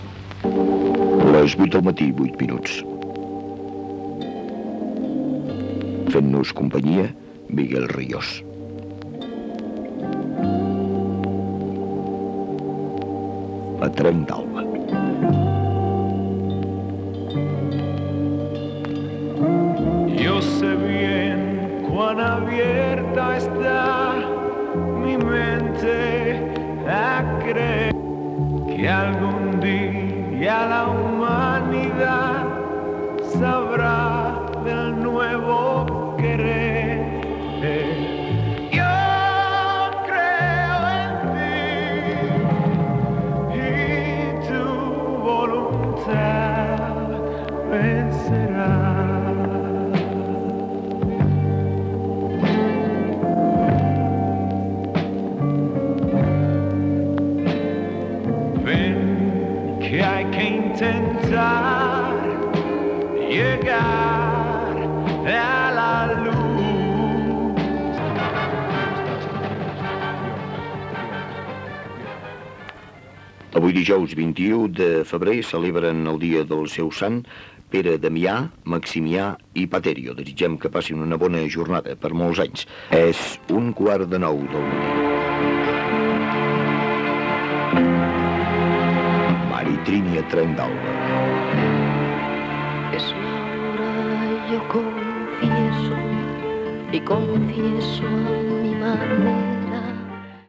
Tema musical, santoral, hora i tema musical Gènere radiofònic Musical